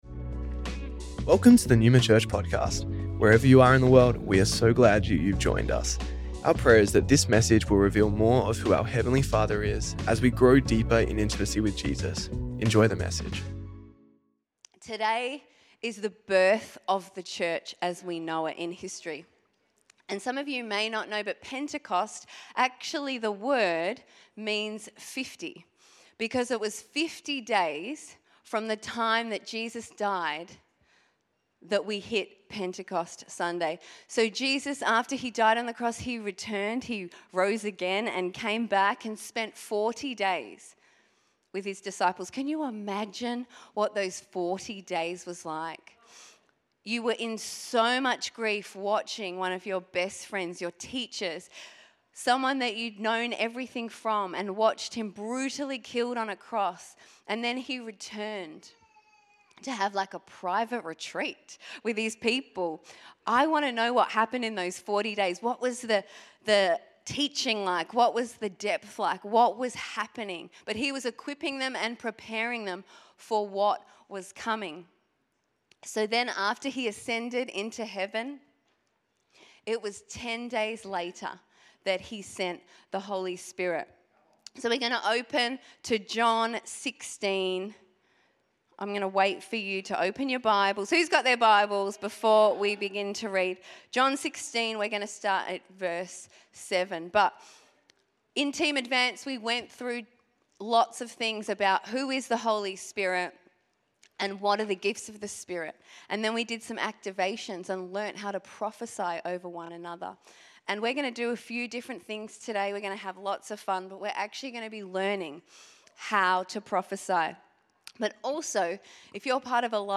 Neuma Church Melbourne South Originally recorded at the 10AM Service on Sunday 8th June 2025